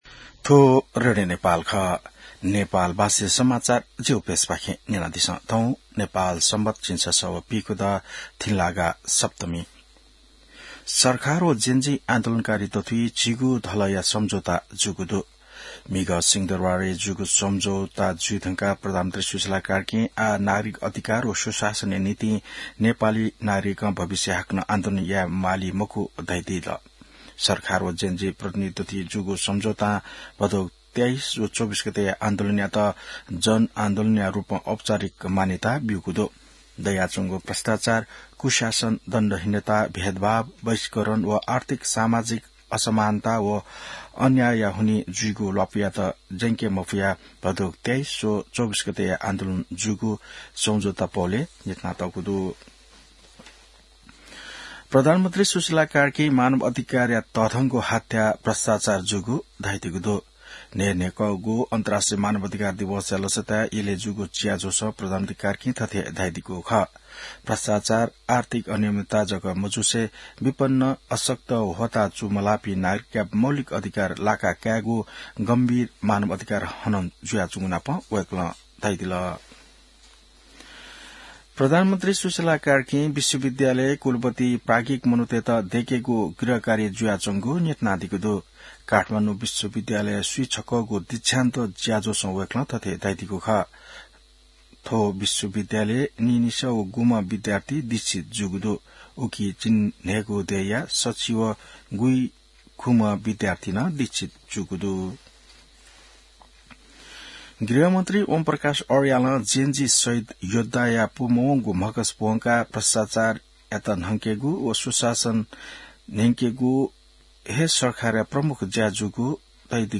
नेपाल भाषामा समाचार : २५ मंसिर , २०८२